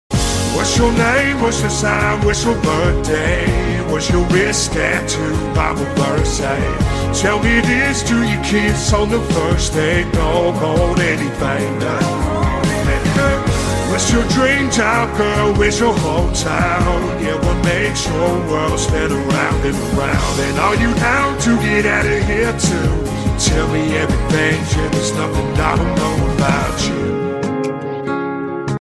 （AI cover）